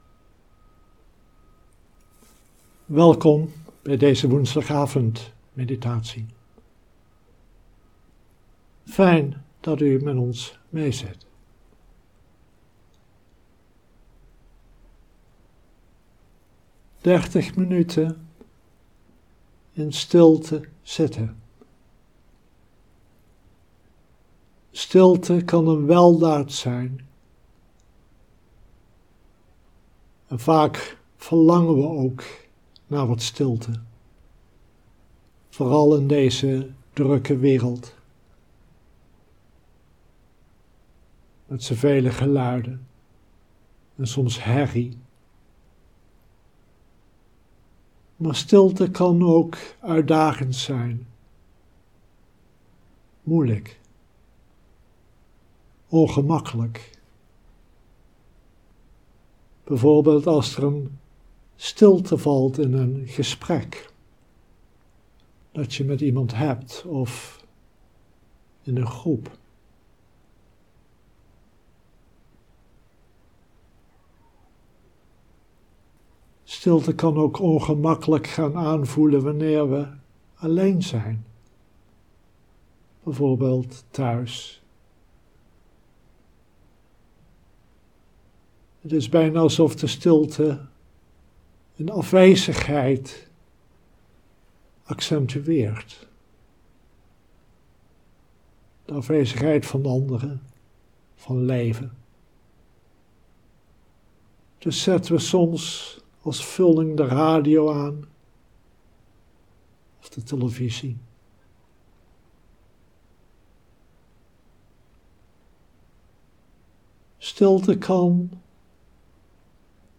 Livestream opname